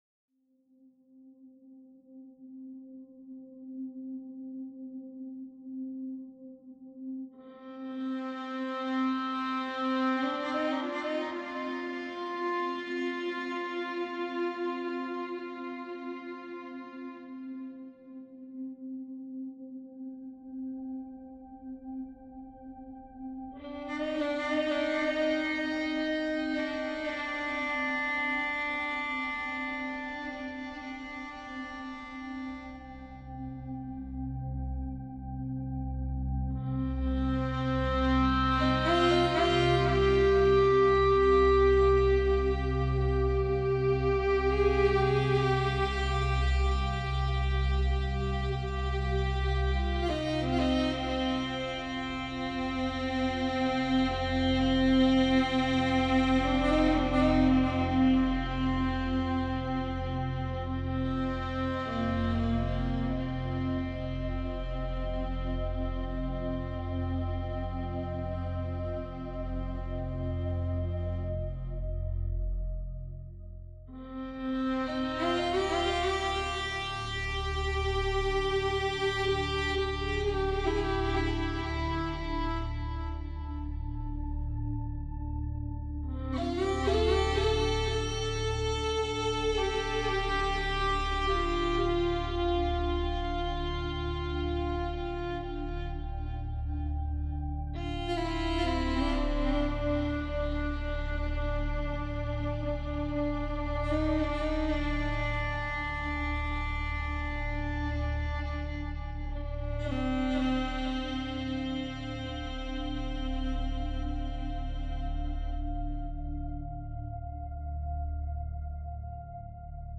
viola and pads